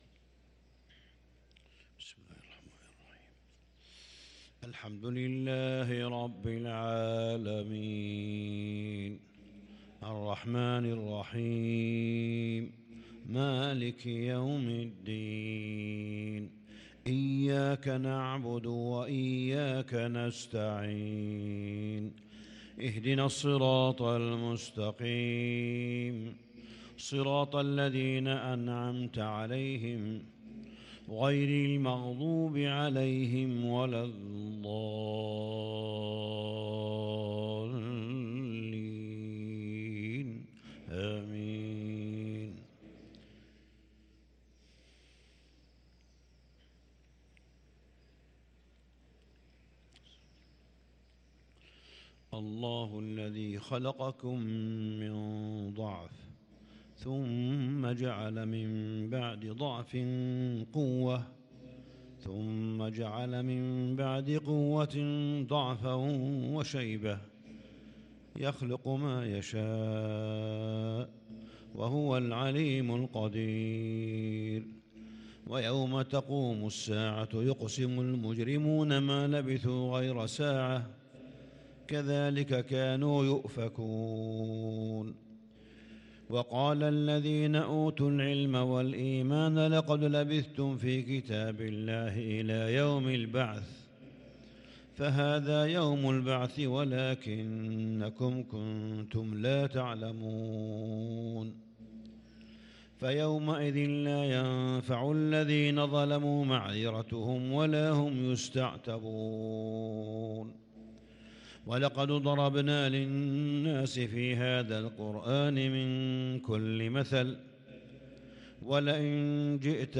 فجر الجمعة 7-9-1443هـ من سورتي الروم و لقمان | fajr prayer from surat AI-Rum & Luqman 8-4-2022 > 1443 🕋 > الفروض - تلاوات الحرمين